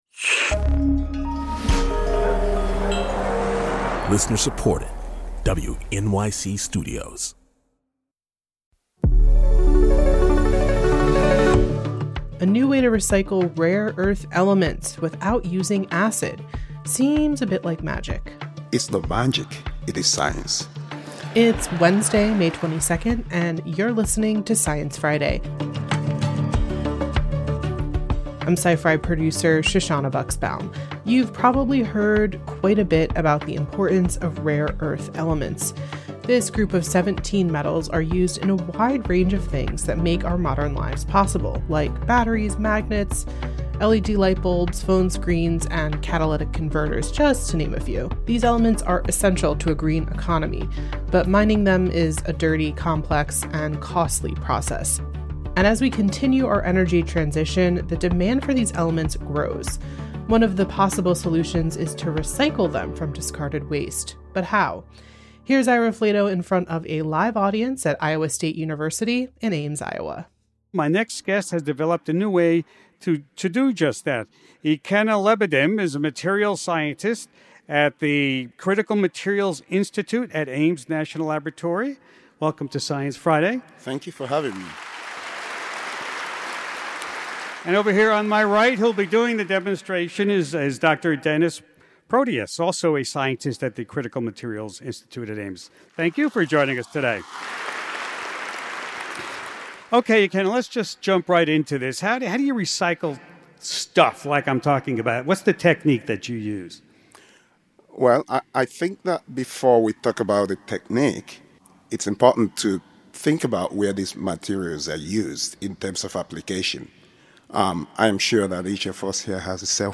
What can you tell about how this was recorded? On stage in Ames, Iowa